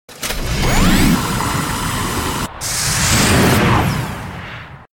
Звук захлопывающейся ловушки призрак втянут дверцы закрываются